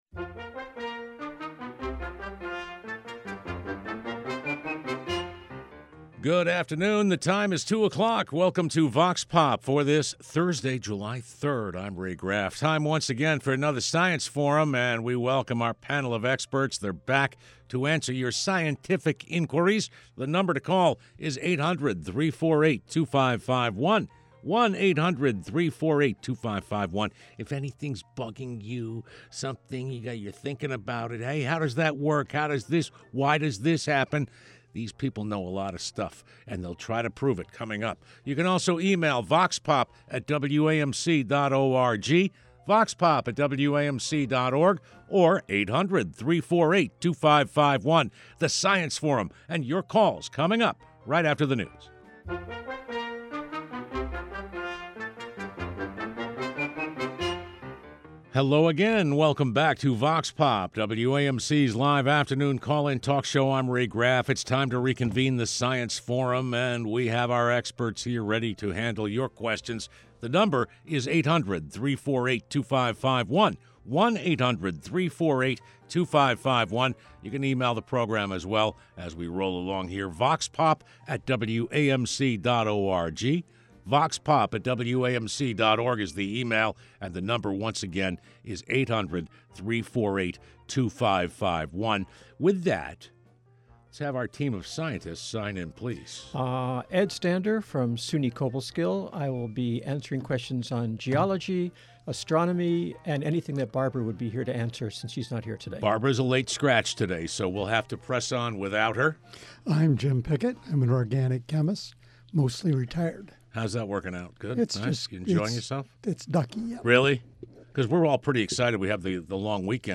1 Science Forum 7/3/25 50:33 Play Pause 2d ago 50:33 Play Pause Play later Play later Lists Like Liked 50:33 The scientists are back to answer your questions!